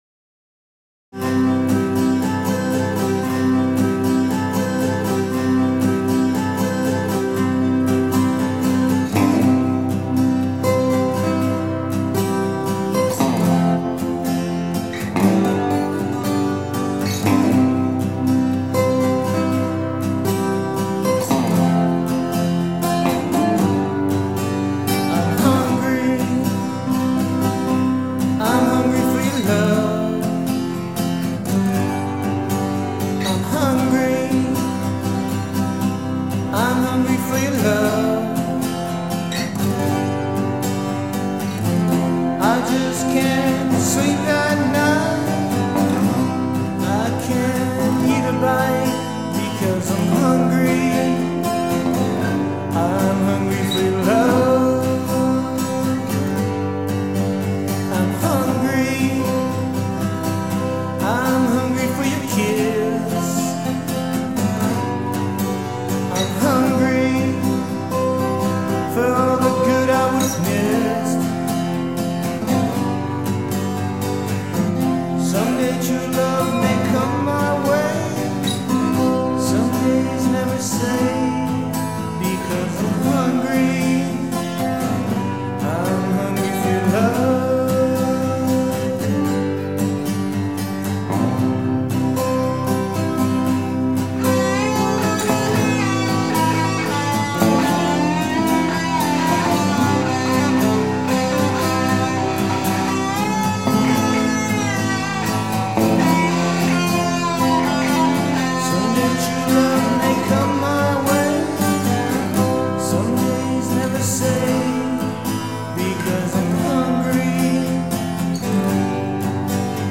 This 1987 8-track recording by
Genre: "sock-o-delic".
Guitars and vocals.
Guitar solo